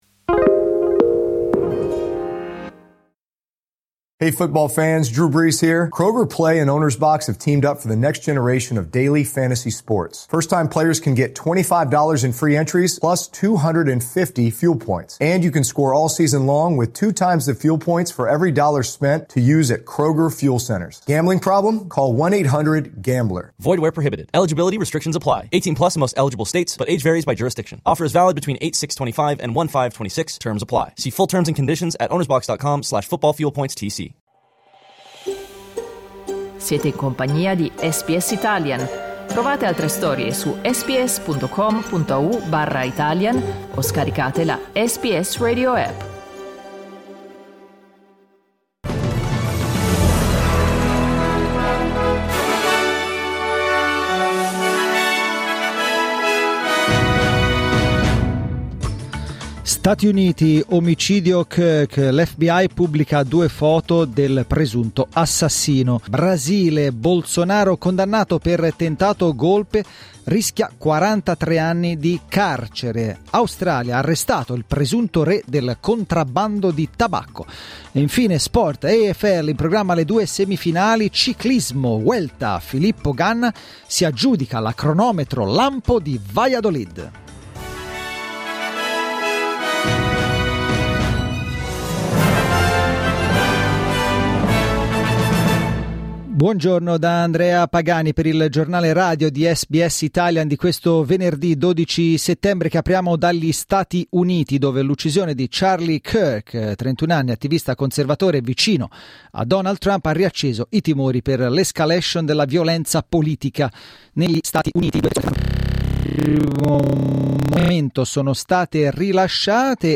Giornale radio venerdì 12 settembre 2025
Il notiziario di SBS in italiano.